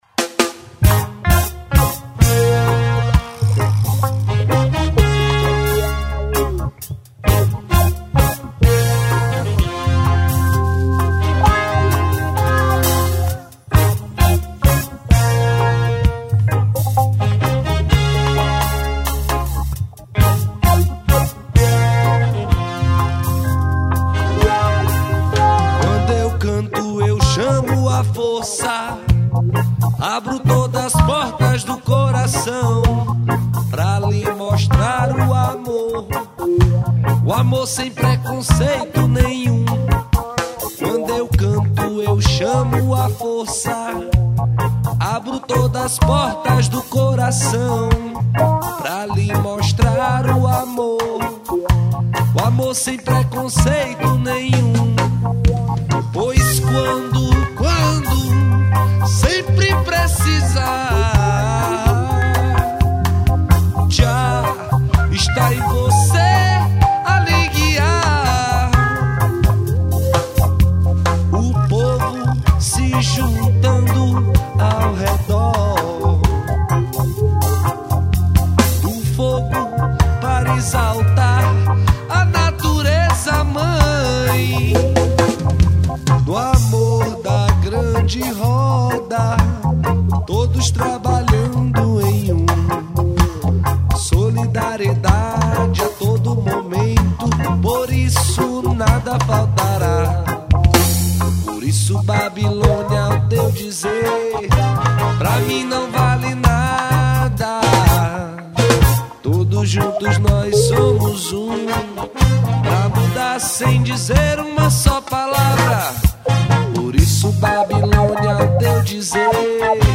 AO VIVO
2122   02:59:00   Faixa:     Reggae
Guitarra, Voz
Escaleta, Teclados
Bateria
Baixo Elétrico 6
Trompete, Vocal
Sax Alto